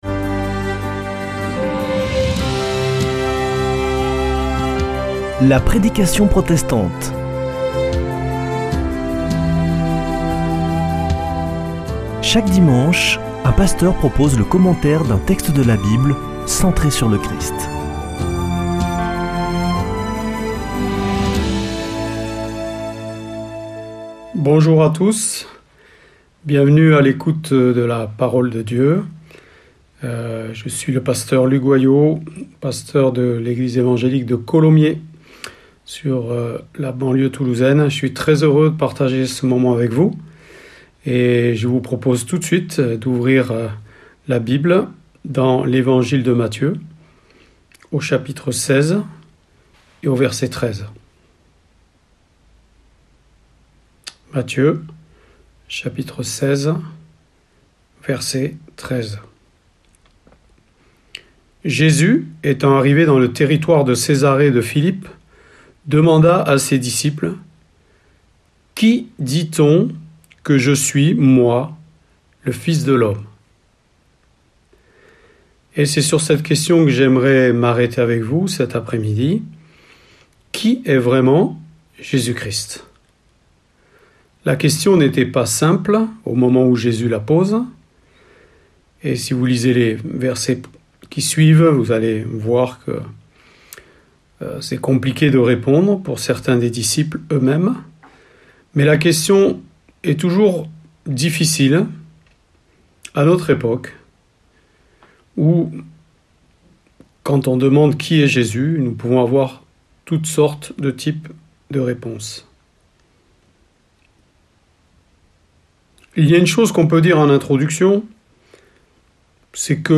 Accueil \ Emissions \ Foi \ Formation \ La prédication protestante \ Qui dites-vous que Je suis ?
Une émission présentée par Des protestants de la région